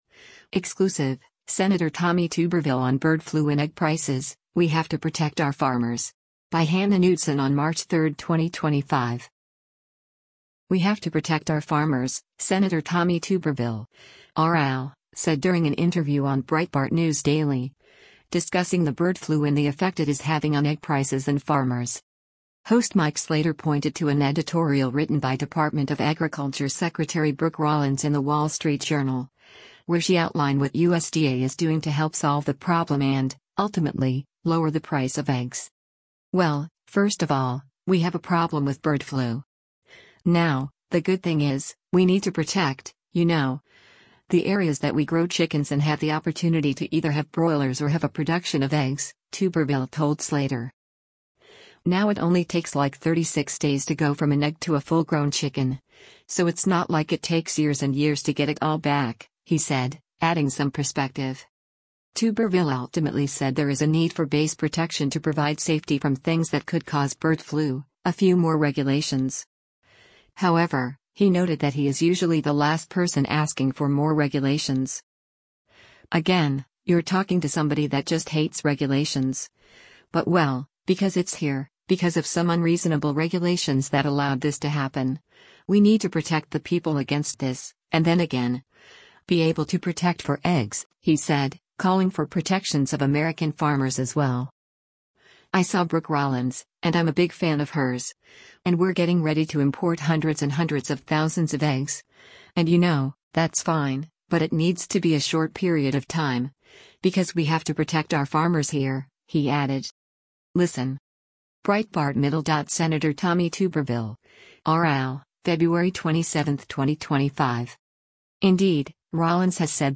“We have to protect our farmers,” Sen. Tommy Tuberville (R-AL) said during an interview on Breitbart News Daily, discussing the bird flu and the effect it is having on egg prices and farmers.